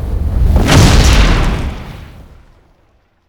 DragonLanding.wav